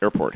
airport.mp3